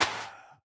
Minecraft Version Minecraft Version snapshot Latest Release | Latest Snapshot snapshot / assets / minecraft / sounds / block / soul_sand / break5.ogg Compare With Compare With Latest Release | Latest Snapshot